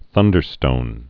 (thŭndər-stōn)